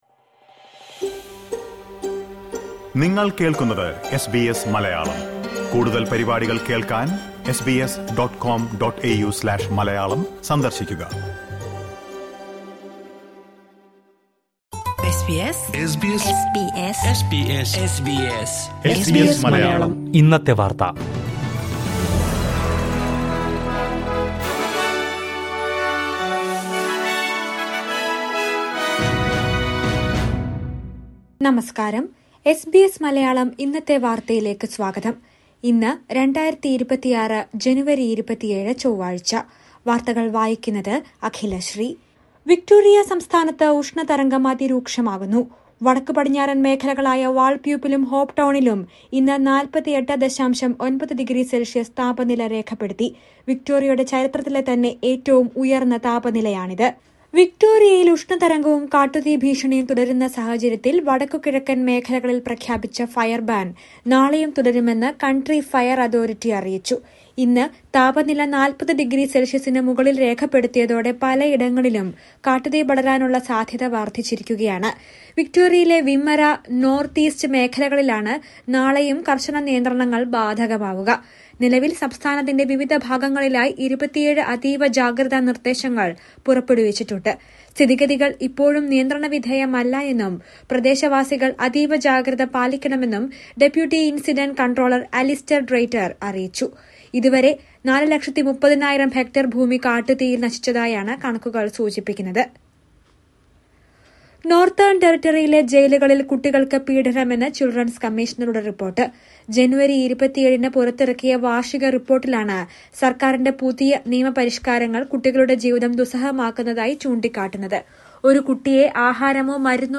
ഇന്നത്തെ വാർത്ത: താപനില 48.9ഡിഗ്രി; റെക്കോർഡ് ചൂടിൽ ഉരുകിയൊലിച്ച് വിക്ടോറിയ